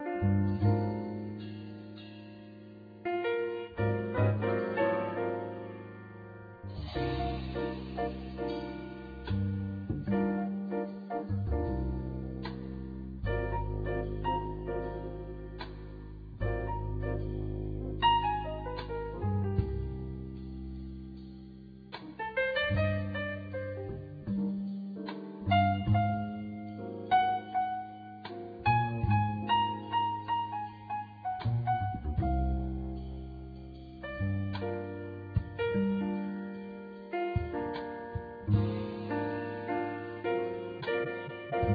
trumpet, flugelhorn
acoustic bass
tenor and soprano saxophone
drums and percussion
synthesizers, piano